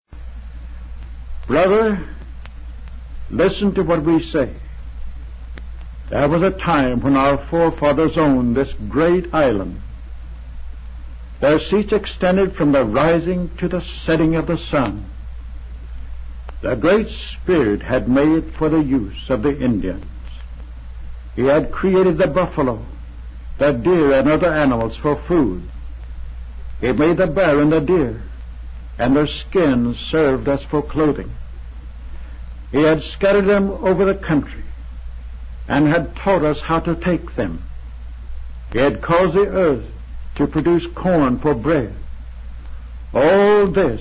Speech delivered 1805 Buffalo Grove New York